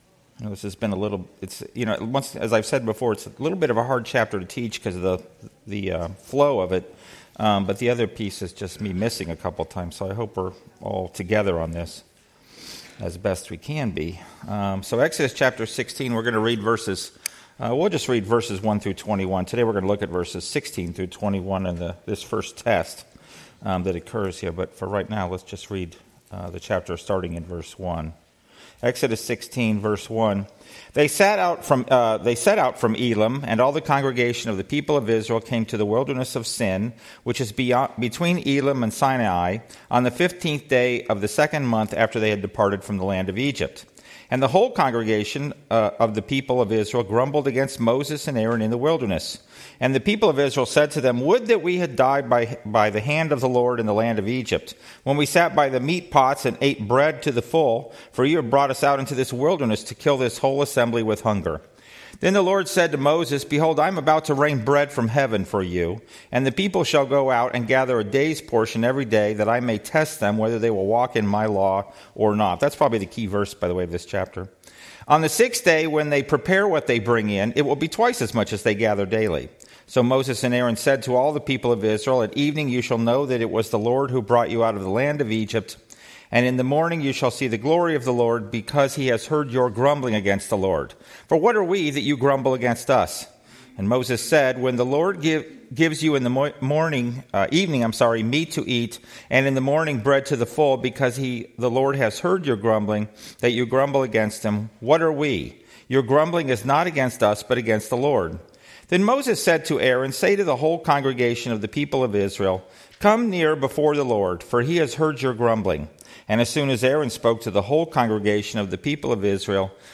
Exodus 16 Service Type: Sunday School « WMBS